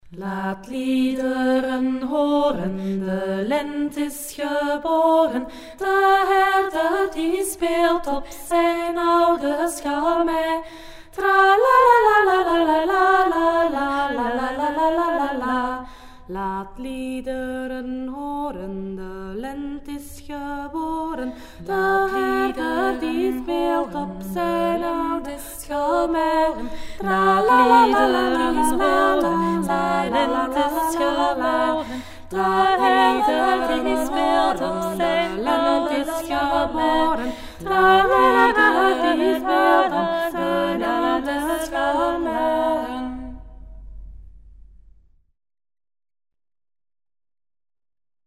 19de eeuw volksliedje